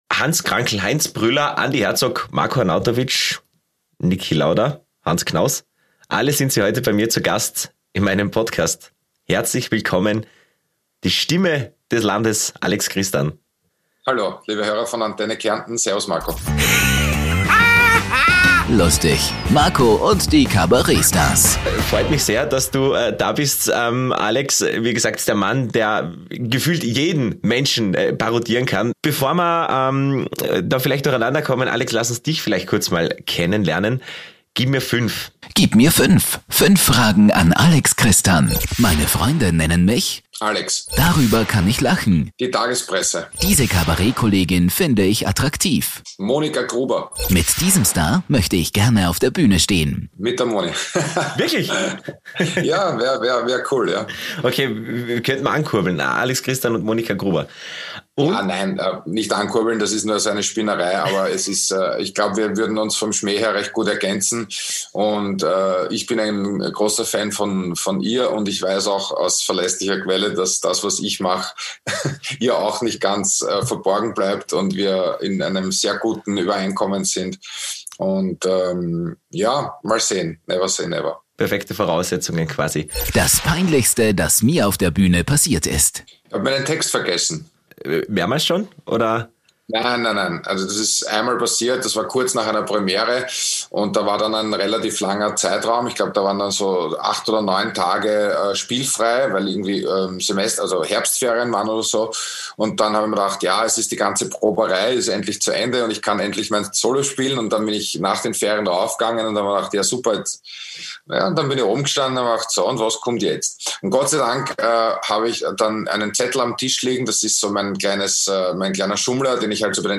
Niemand parodiert Stimmen so gut wie er. Das zeigt er uns auch wieder in der neuen Podcastfolge. Er erklärt auch, was das Geheimnis einer guten Parodie ist und an welchen Stimmen soger ER gescheitert ist.